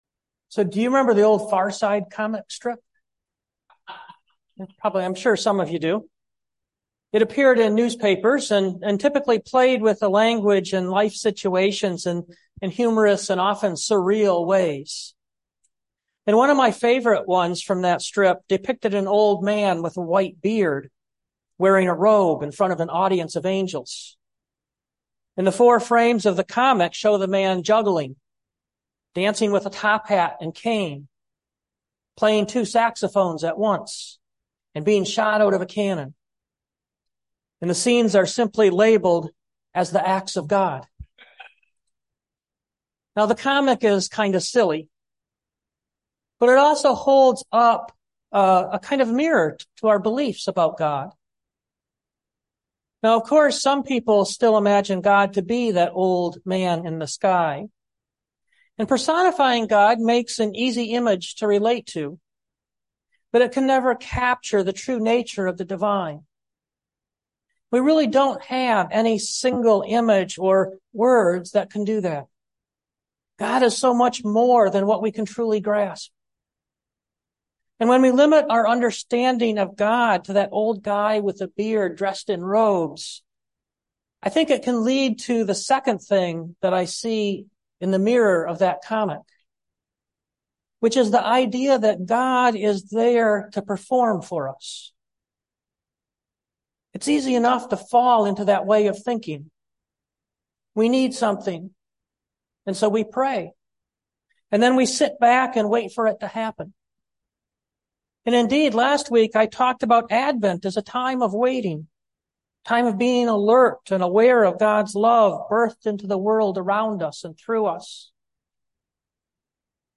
2023 Preparing the Way Preacher